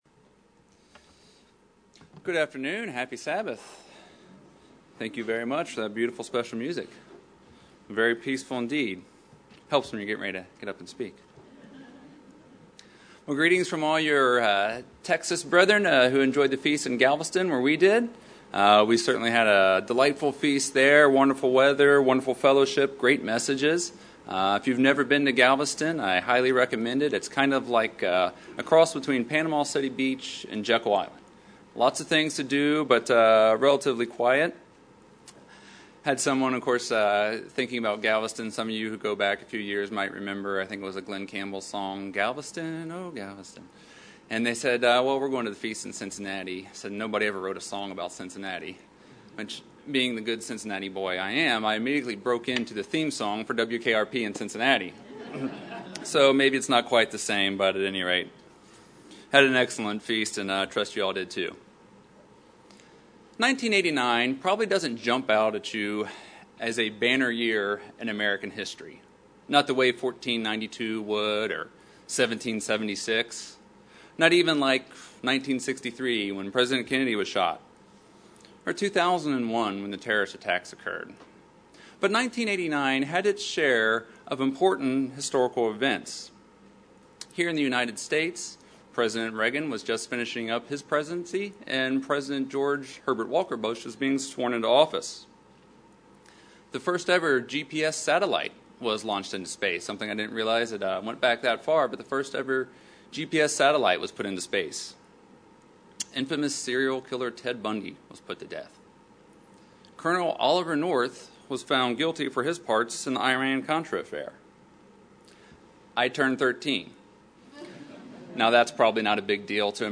Sermons
Given in Cincinnati East, OH